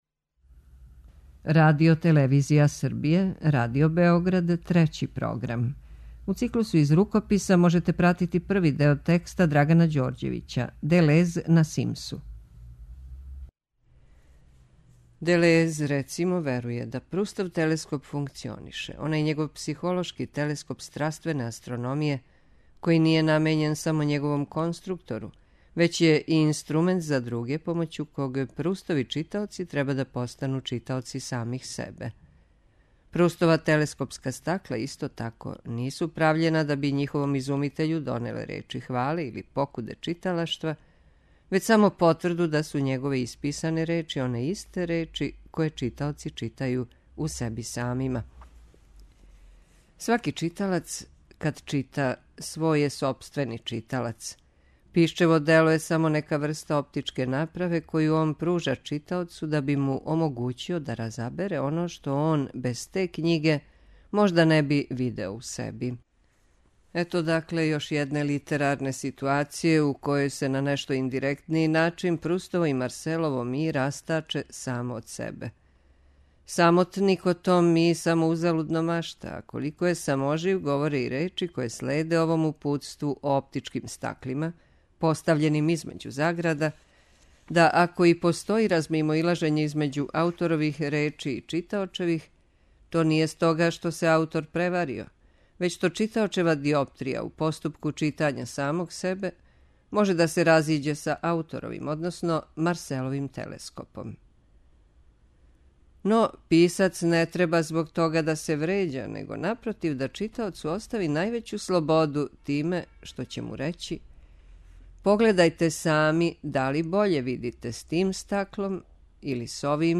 преузми : 21.56 MB Књига за слушање Autor: Трећи програм Циклус „Књига за слушање” на програму је сваког дана, од 23.45 сати.